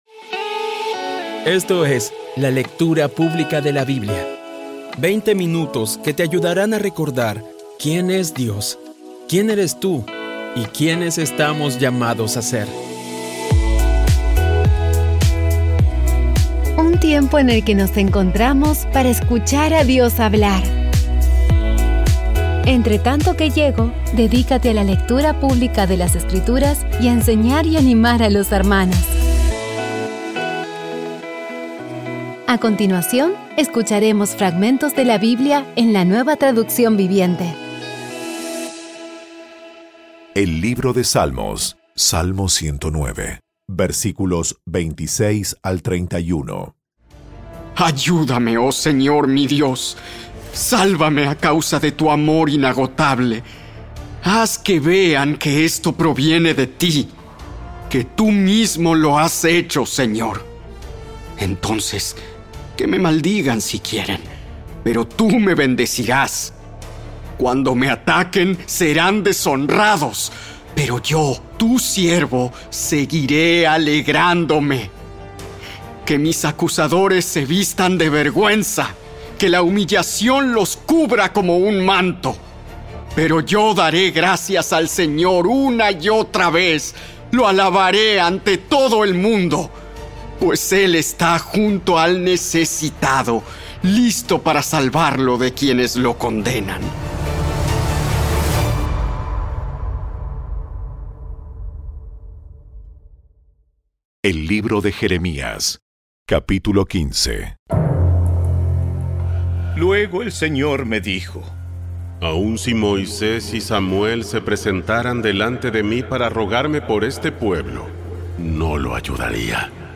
Audio Biblia Dramatizada Episodio 278
Poco a poco y con las maravillosas voces actuadas de los protagonistas vas degustando las palabras de esa guía que Dios nos dio.